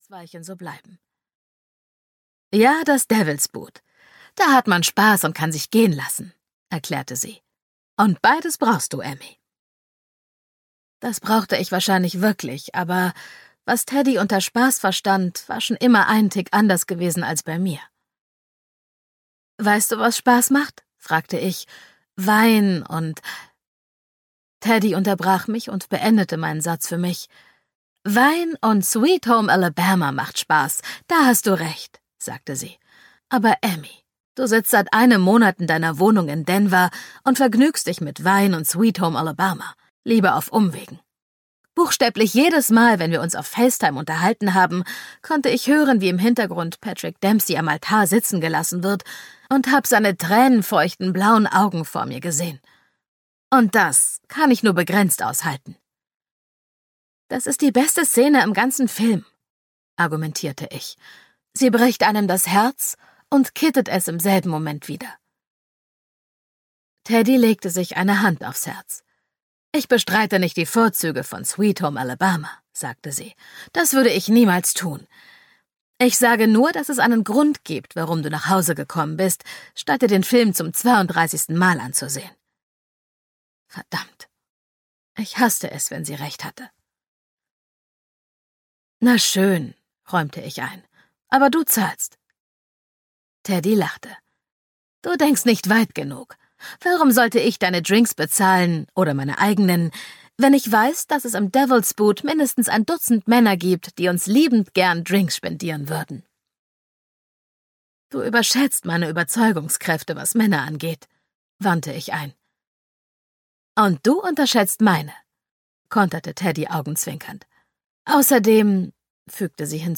Done and Dusted (DE) audiokniha
Ukázka z knihy